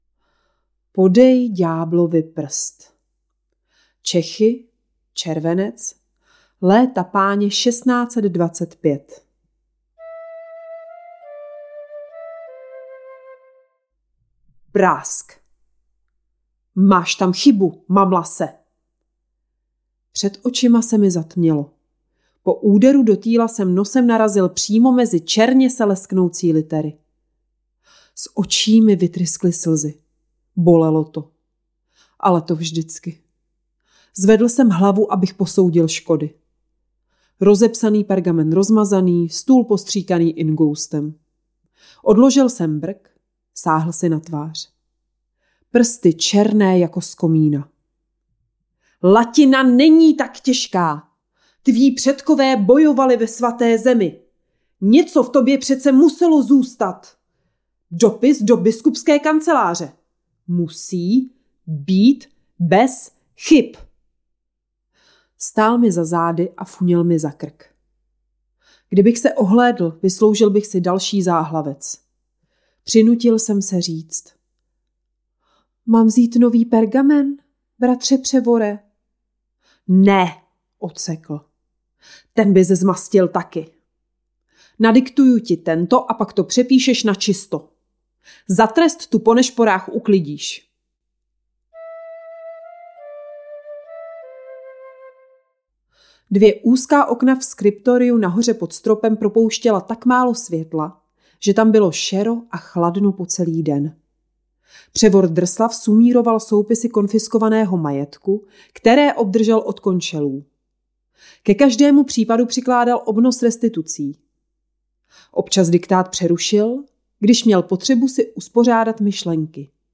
Za-devatero-mlhovinami-ukazka.mp3